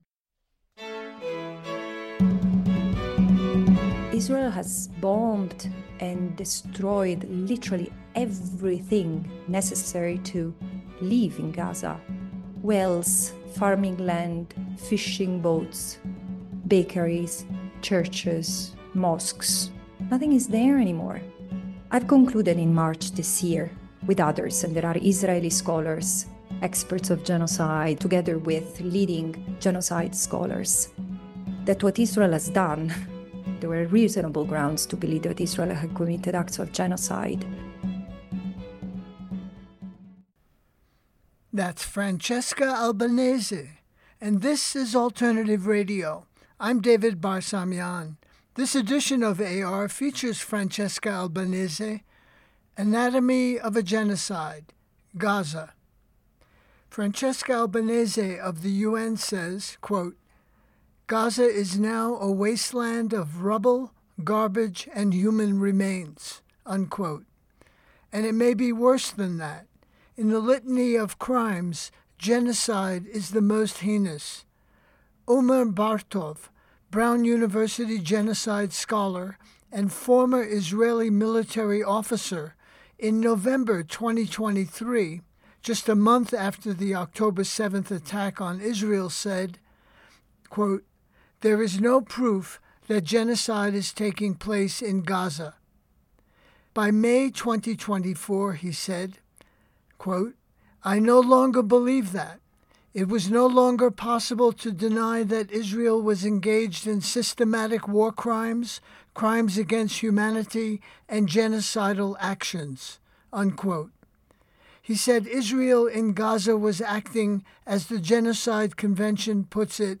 File Information Listen (h:mm:ss) 0:57:00 Francesca Albanese Anatomy of a Genocide: Gaza Download (0) Albanese-GenocideGaza_pacNCRA.mp3 54,743k 128kbps Mono Comments: Boulder, CO Listen All